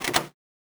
Item Lock (2).wav